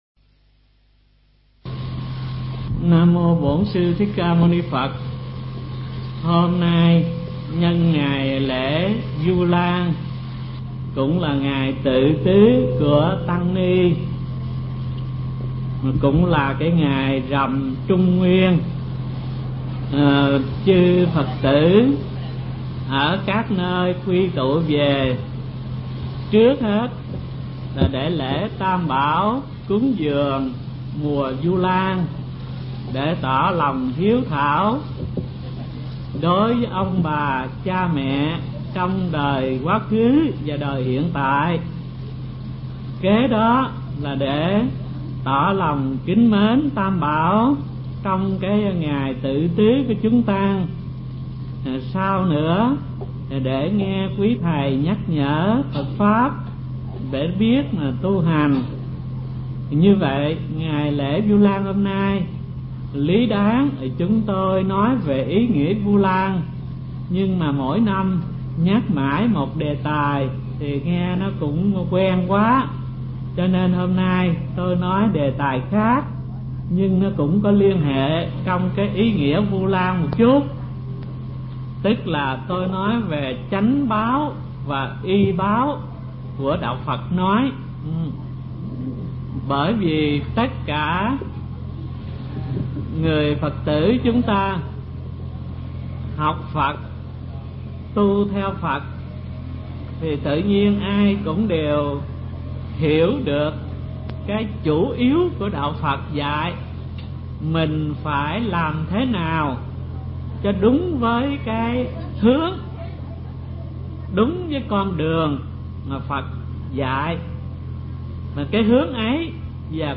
Pháp Âm Chánh Báo Và Y Báo – Hòa Thượng Thích Thanh Từ mp3